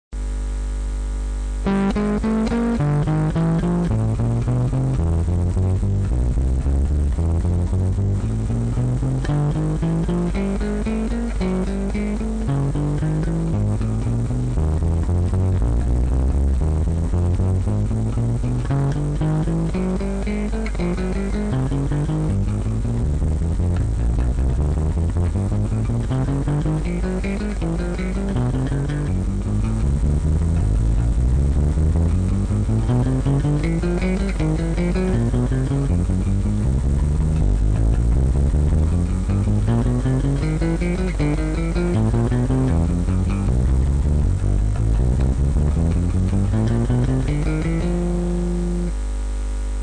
rythme à appliquer:  croche ou double croche avec un tempo donné par le métronome